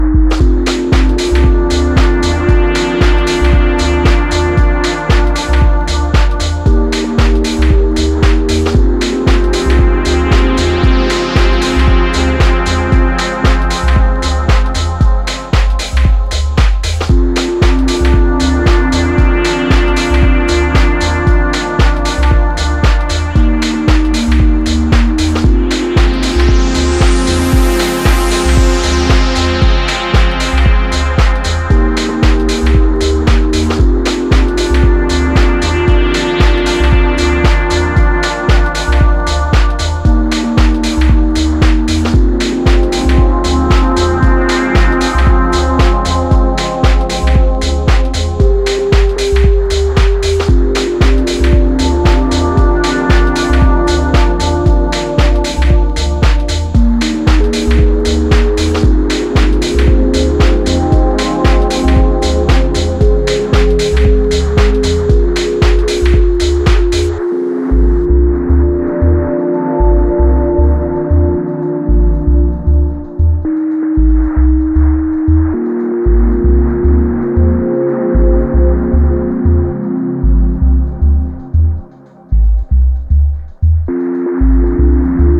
deeper than deep house